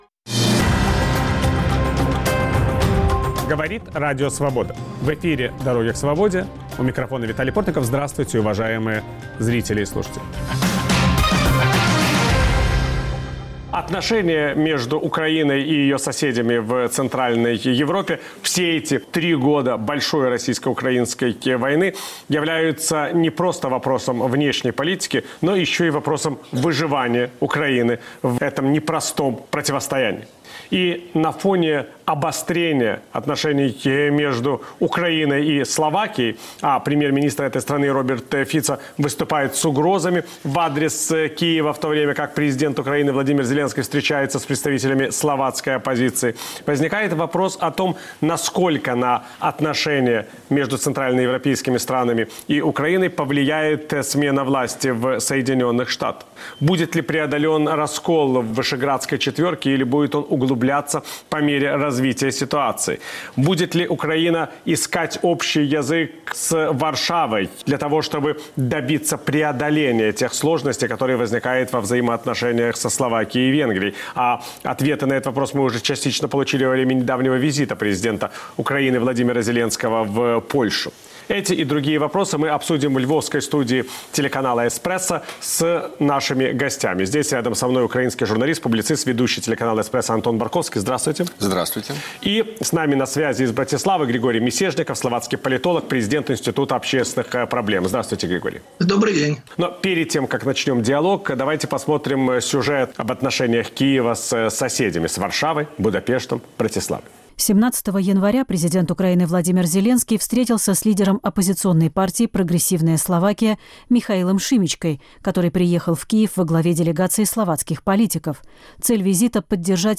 В студии - Виталий Портников и его гости. Удастся ли Украине противостоять российской военной агрессии? Как будут развиваться отношения между народами Украины и России?